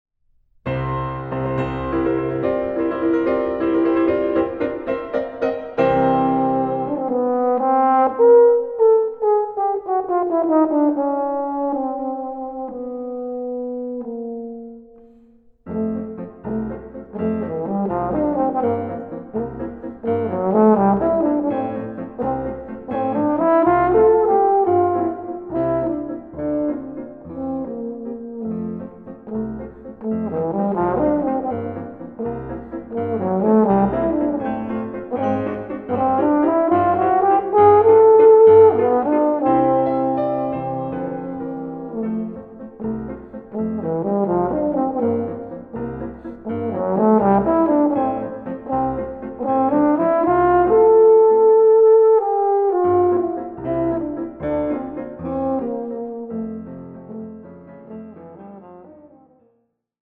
Recording: Mendelssohn-Saal, Gewandhaus Leipzig, 2025
Version for Wunderhorn and Piano